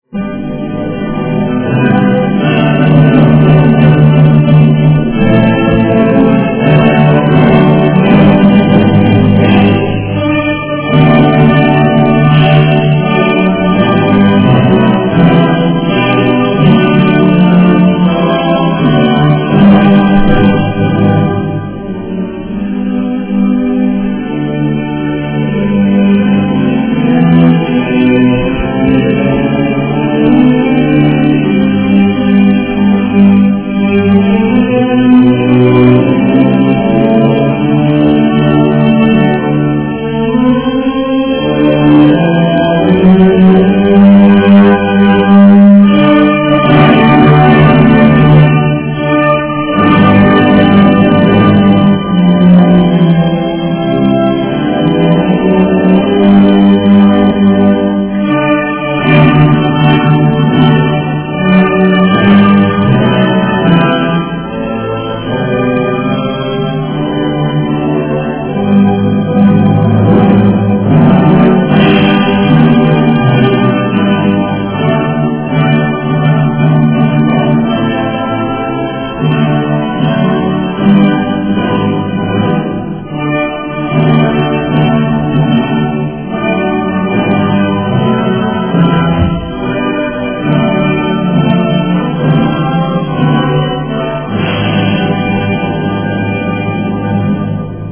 هذا الرابط للسلام الوطني بالموسيقى